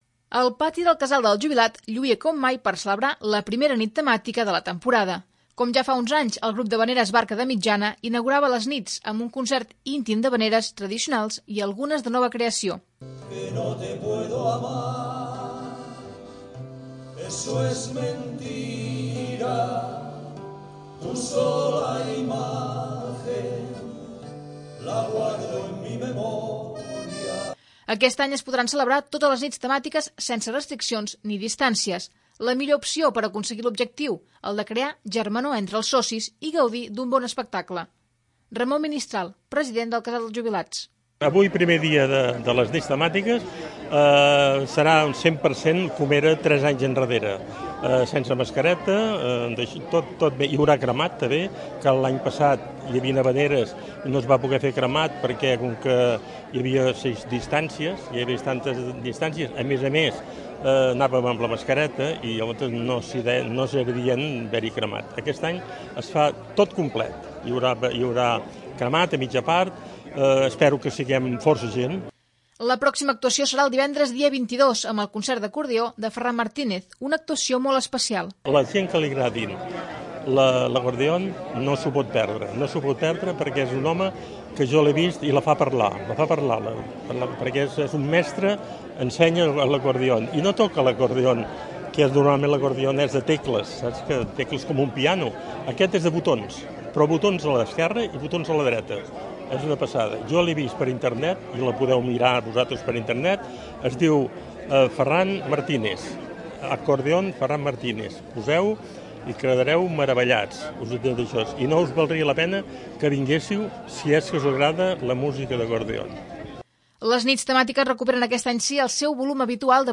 El Casal del jubilat, celebra la primera nit temàtica amb la tradicional actuació del grup Barca de Mitjana.
Com ja fa uns anys el grup d'havaneres Barca de Mitjana, inaugurava les nits amb un concert íntim d'havaneres tradicionals i algunes de nova creació.